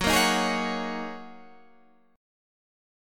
Gb6add9 chord